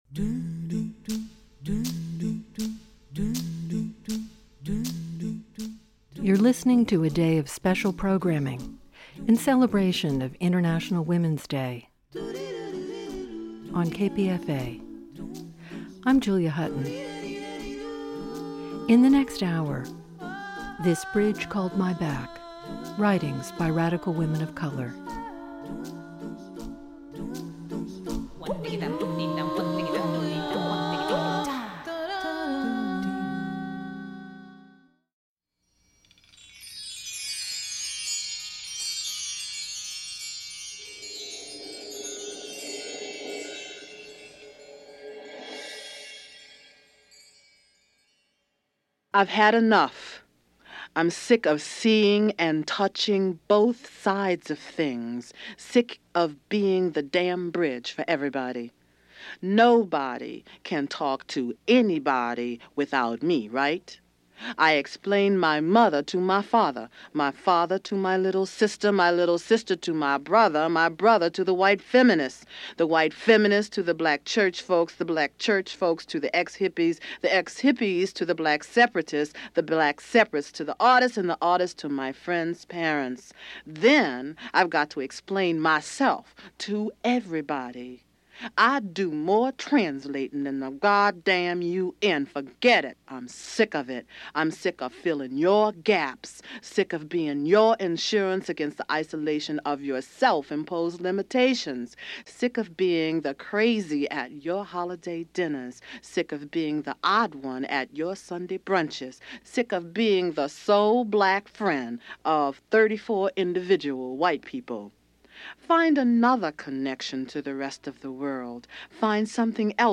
Writers read from the poems, essays and personal narratives they contributed to this remarkable anthology.